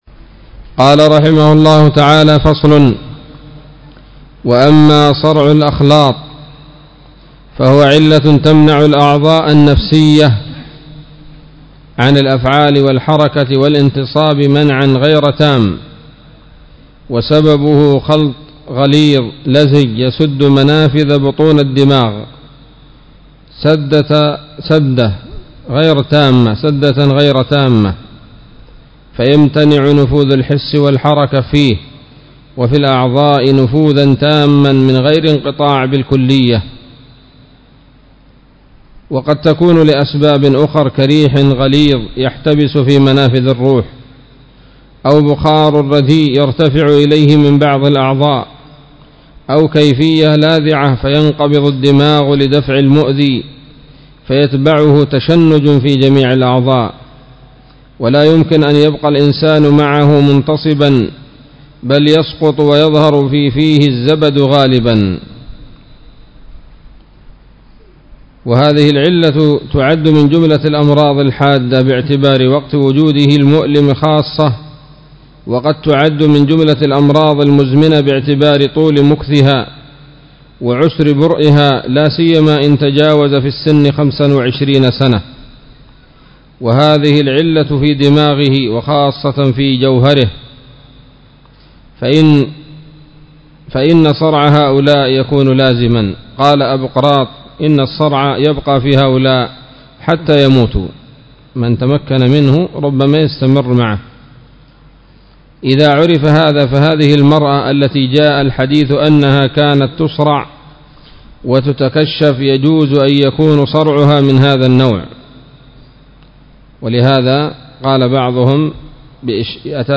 الدرس التاسع عشر من كتاب الطب النبوي لابن القيم